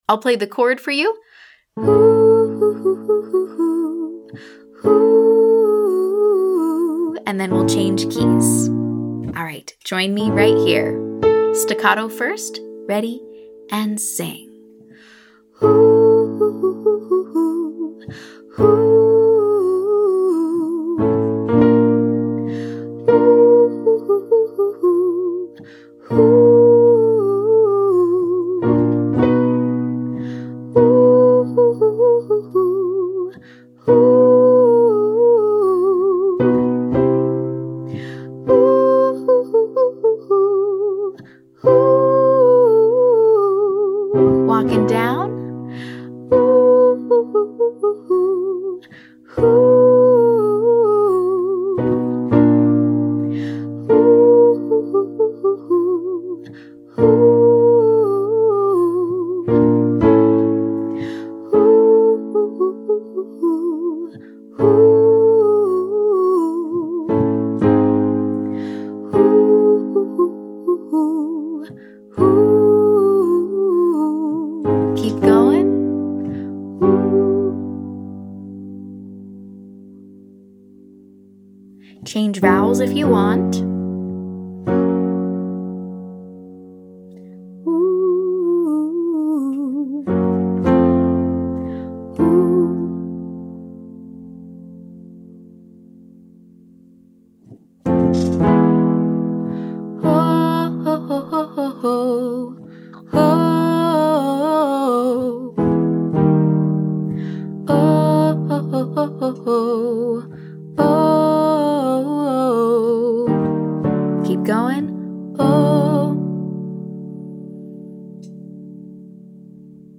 Beginner Riffs And Runs For High Voices 3C